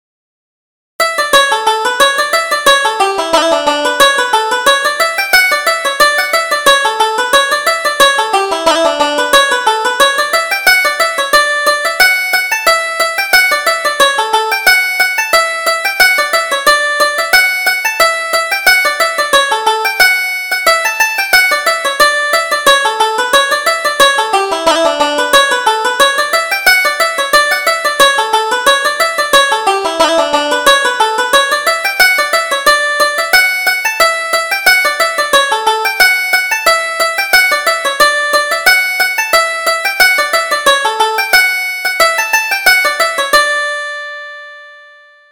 Reel: Your Mother's Fair Pet